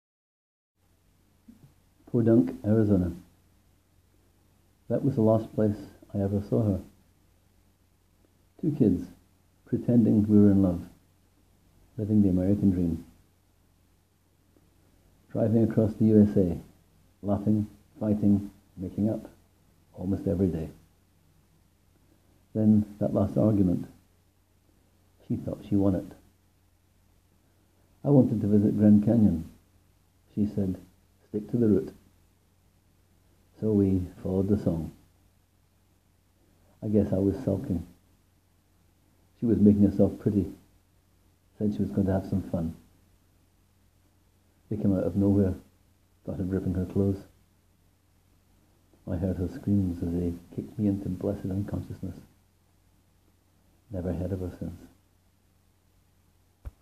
Click here to hear me read my 1-minute story:
Violent story but narrated in such a matter of fact tone.